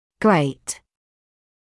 [greɪt][грэйт]большой, объемный (гл.об. о нематериальных понятиях); великий